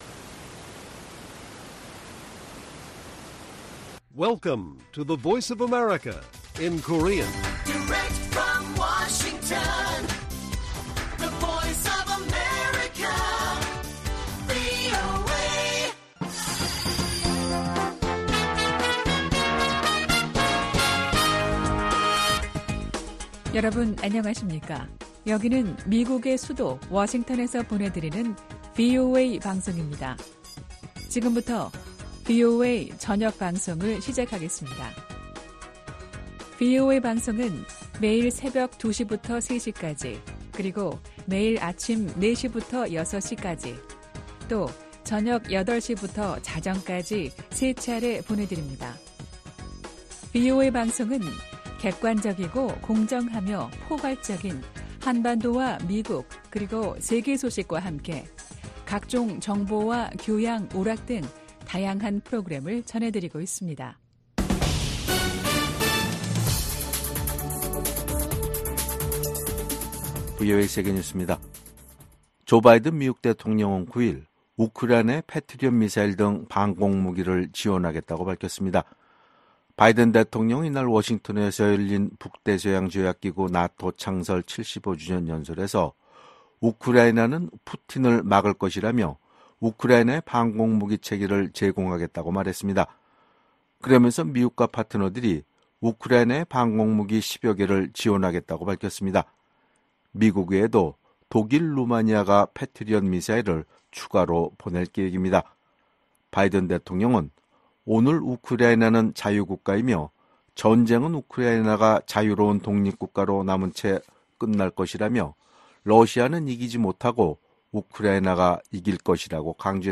VOA 한국어 간판 뉴스 프로그램 '뉴스 투데이', 2024년 7월 10일 1부 방송입니다. 워싱턴에서 북대서양조약기구(NATO∙나토) 정상회의가 개막한 가운데 조 바이든 미국 대통령 러시아의 침략 전쟁은 실패했다고 지적했습니다. 미국 북 핵 수석대표인 정 박 대북고위관리가 최근 사임했다고 국무부가 밝혔습니다. 탈북민들이 미국 의회에서 열린 증언 행사에서 북한 주민들이 세상을 제대로 알고 변화를 주도하도록 외부 정보 유입을 강화해야 한다고 촉구했습니다.